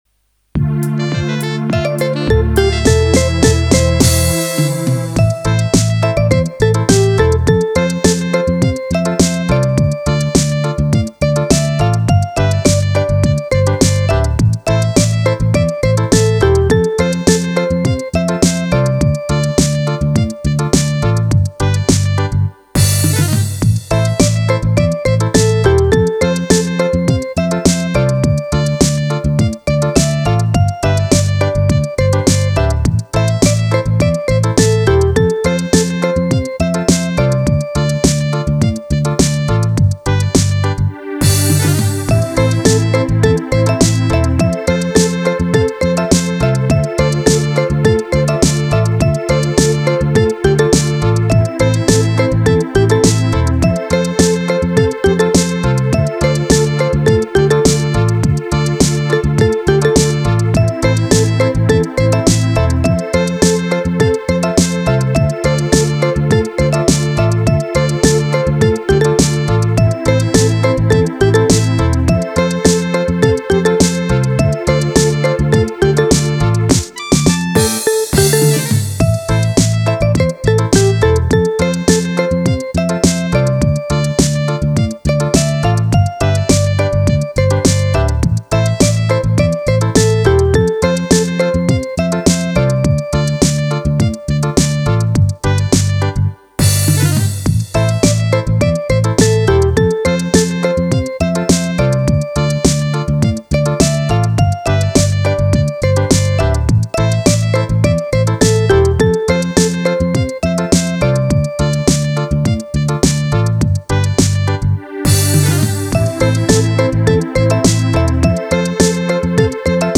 Soul & Funk
Vanaf de eerste tel zette het ritme meteen iets speels en aanstekelijk in gang.
Het gaat om 078 Jazz/Funk, met een tempo van 104 BPM.